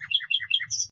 SFX_Bird_2.ogg